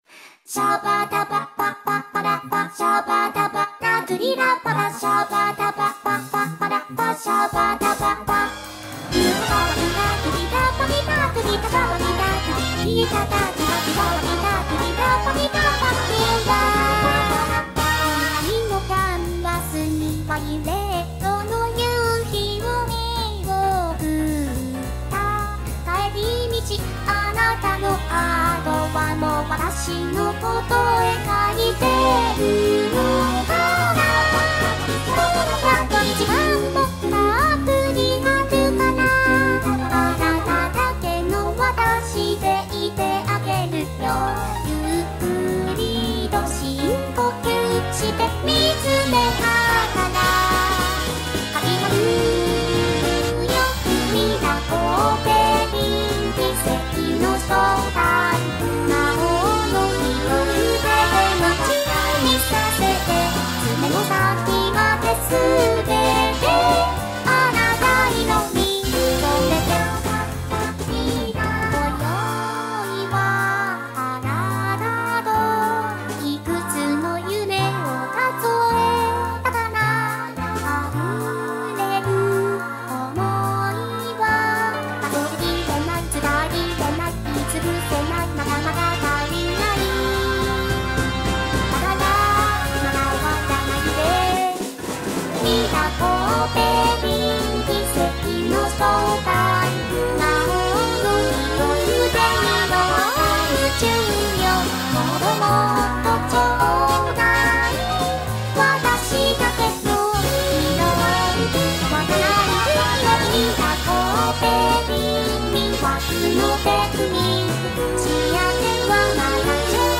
BPM215
Audio QualityPerfect (High Quality)
jazz
organic instrumentation